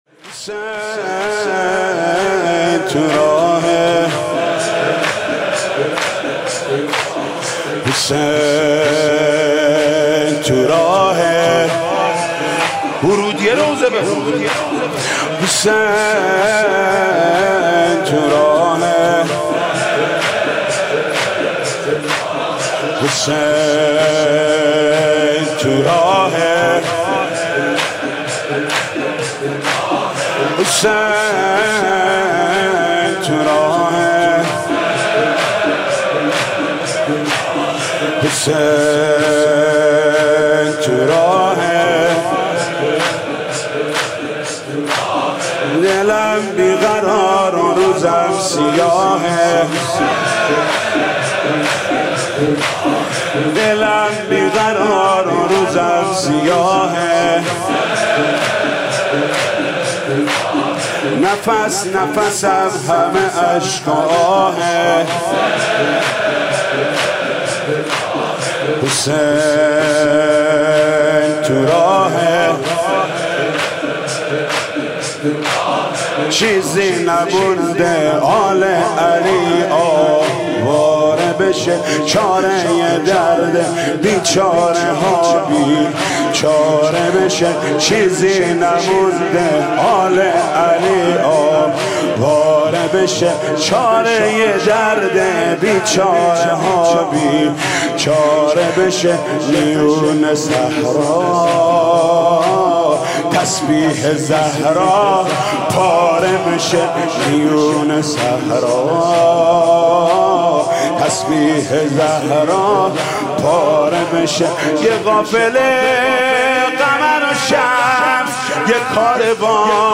مداحی شروع محرم